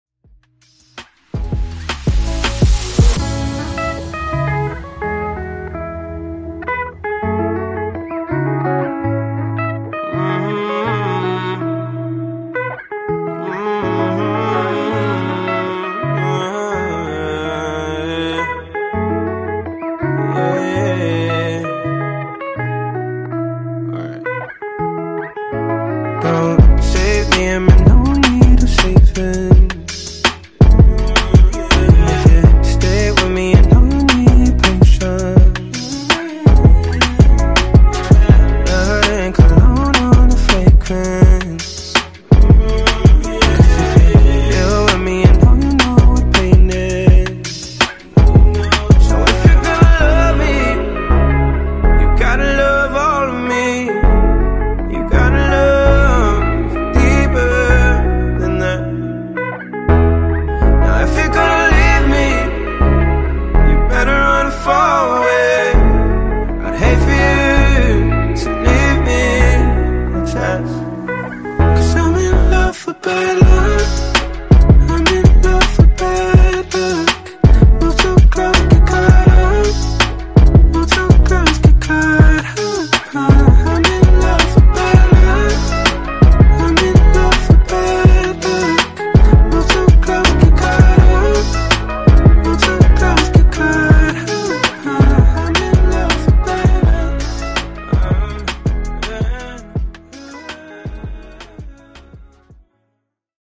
Genre: RE-DRUM Version: Clean BPM: 113 Time